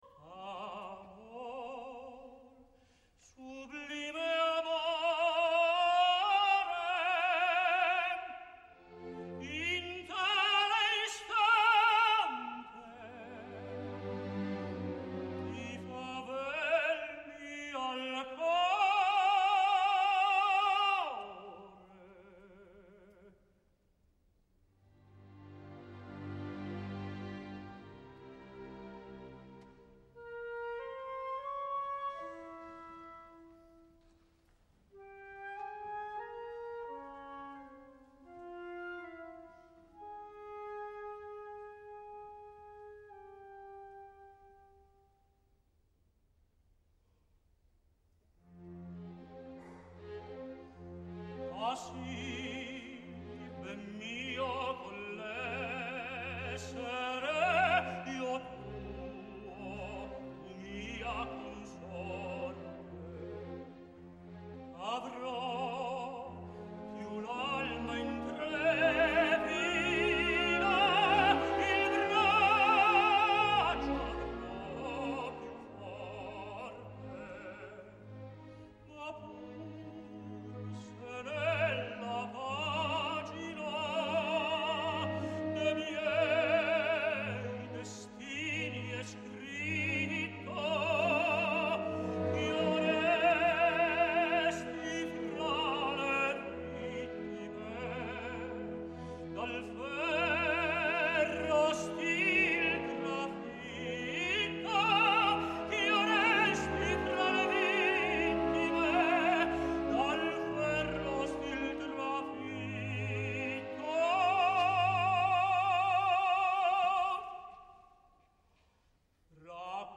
Avui, per començar una nova setmana us deixo un Trovatore que es va interpretar en versió de concert al Concert-gebouw d’Amsterdam, dissabte passat dia 14 de setembre.
Aquí el teniu en allò que voleu escoltar tots, “Ah, si ben mio…Di quella pira” amb les dues estrofes
tenor
Concertgebouw Amsterdam 14.09.2013